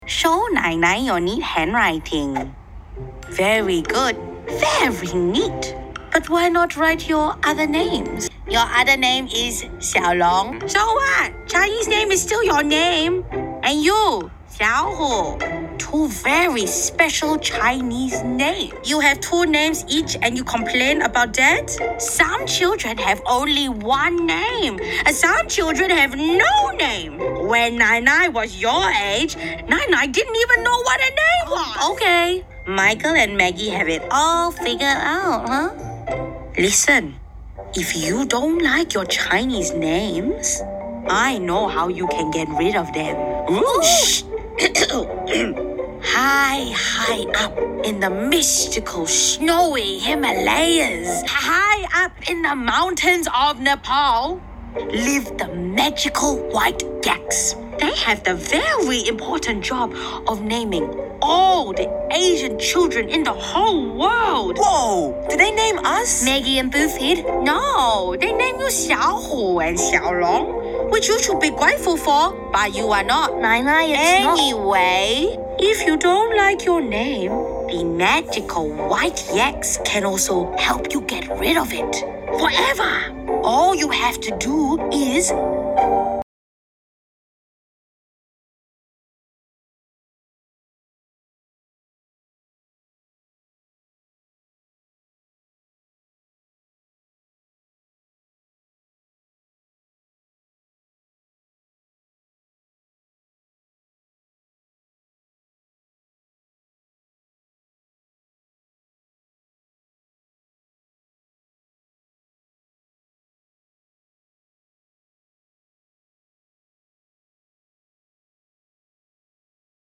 husky